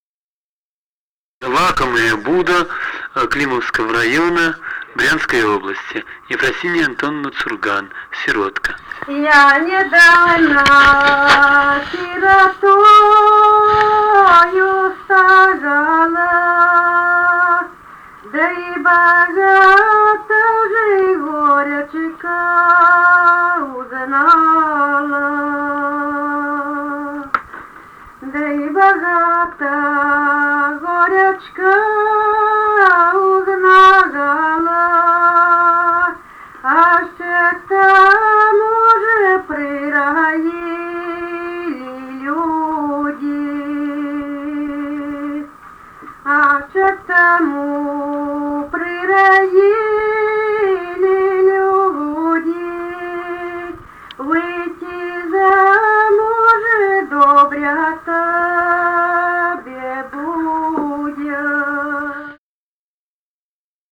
Музыкальный фольклор Климовского района 038. «Я недавно сиротою стала» (лирическая).
Записали участники экспедиции